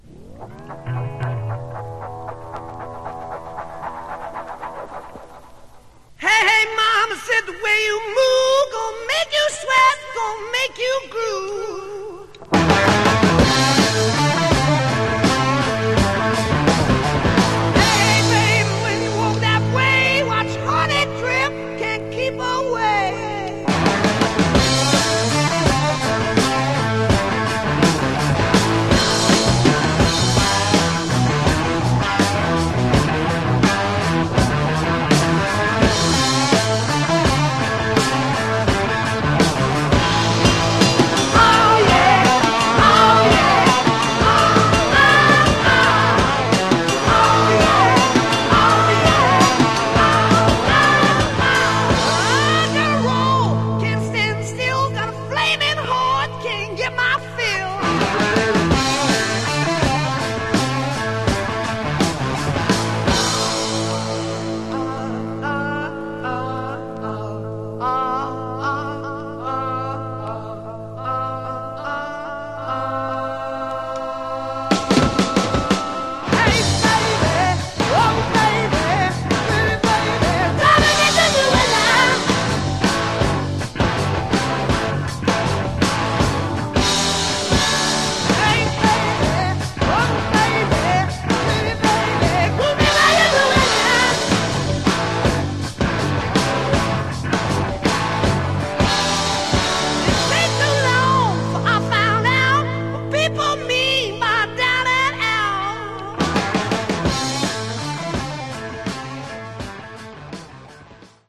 Take a listen to the mp3 snippet I made of the Mono side.